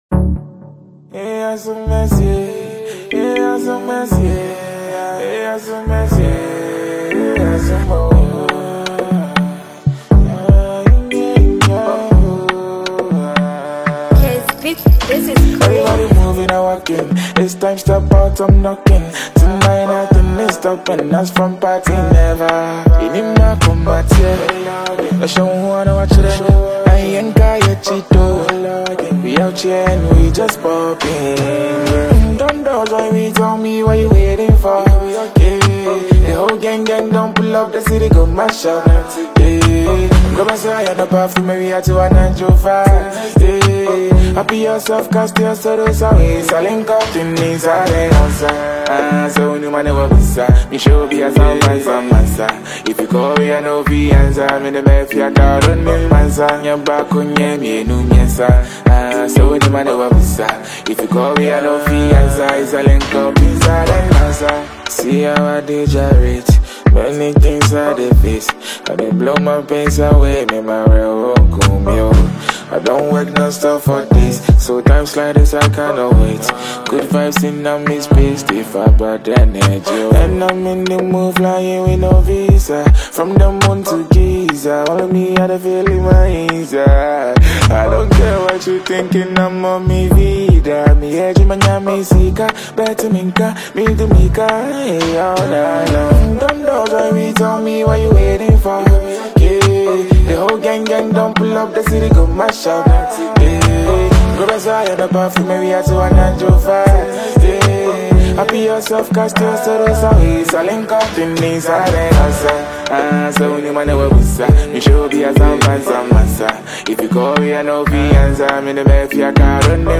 Ghana Music
the song features lush synth pads and a groovy bassline